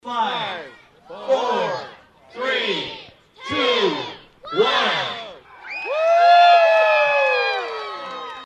The 20th annual event was followed by the lighting of the Mayor’s Christmas tree in Triangle Park…
Xmas-Countdown-.mp3